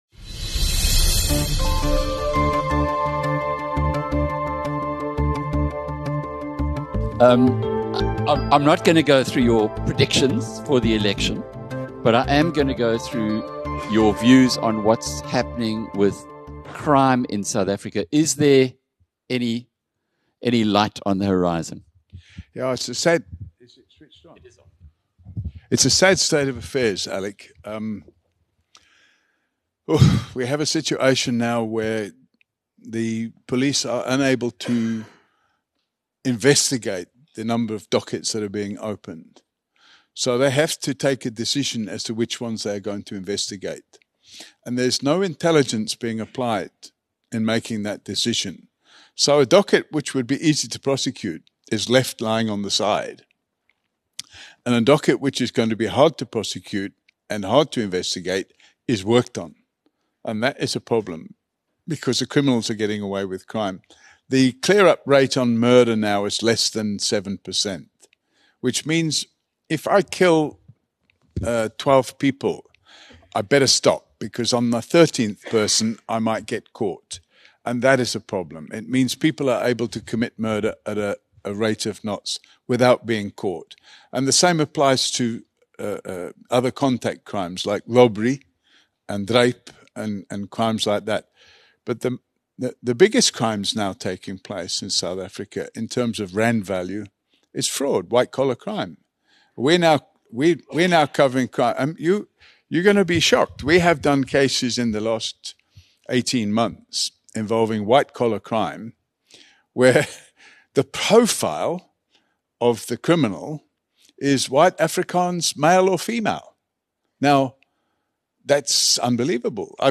During a Q&A session at the BizNews conference in London